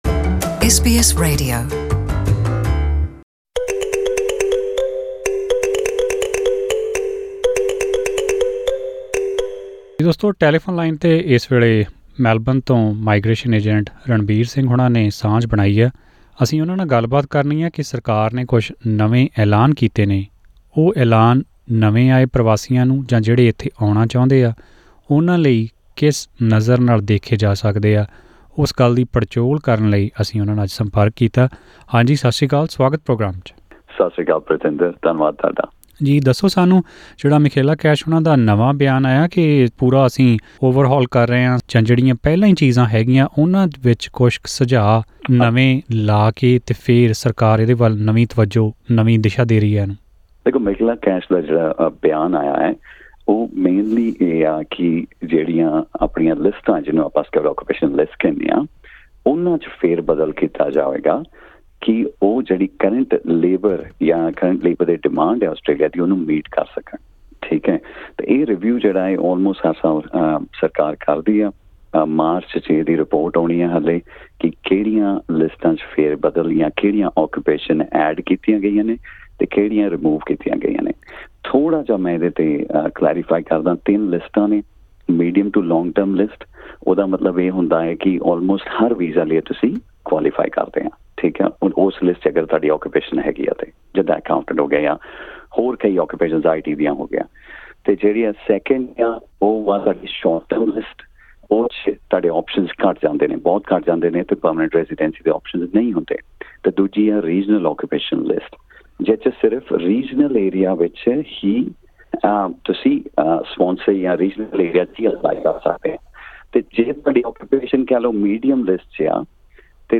Australia’s skilled-migration occupation lists are set to be ‘overhauled’ in the upcoming shake-up of the work visas. Listen to our conversation with a registered migration agent to know what implications it will have on the international students and the other prospective visa applicants.